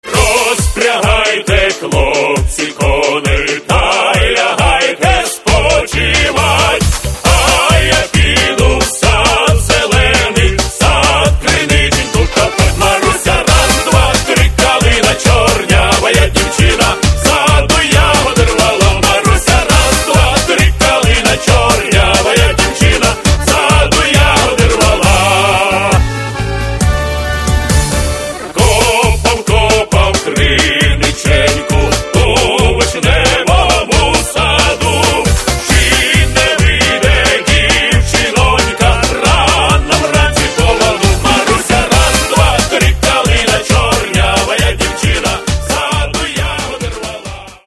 Каталог -> Эстрада -> Группы